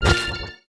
Index of /App/sound/monster/ice_snow_monster
damage_1.wav